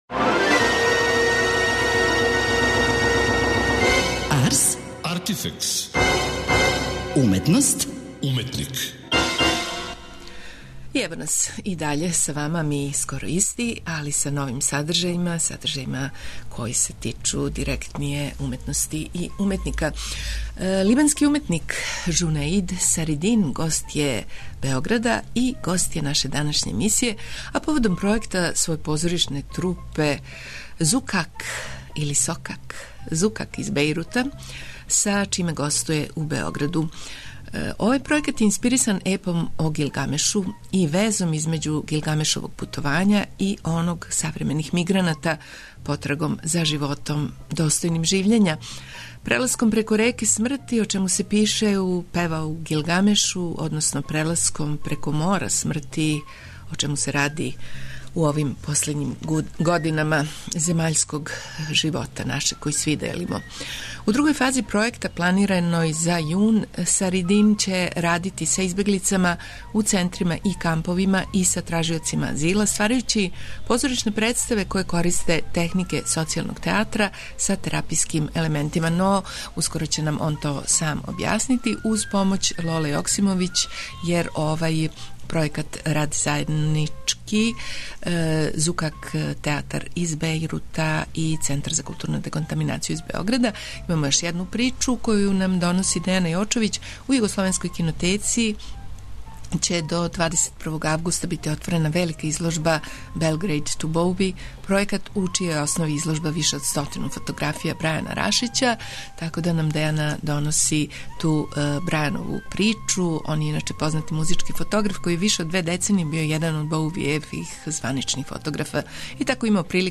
Либански уметник